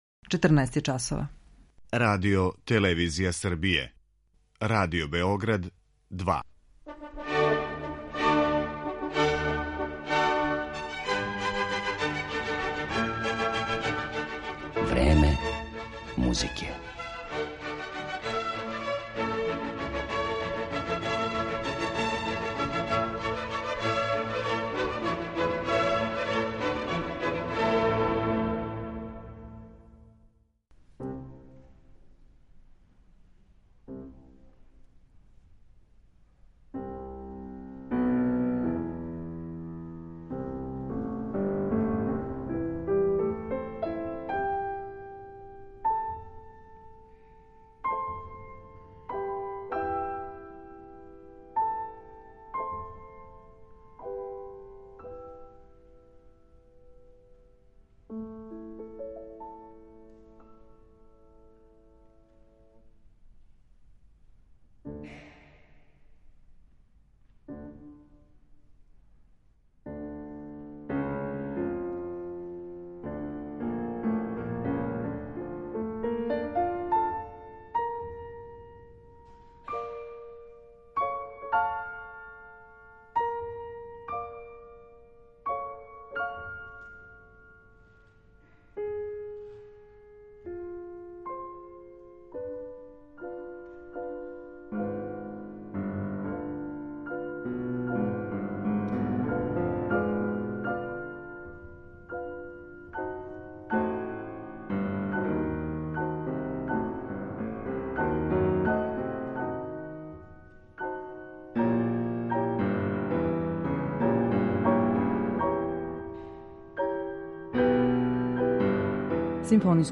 kompozicije za dva klavira i klavir četvororučno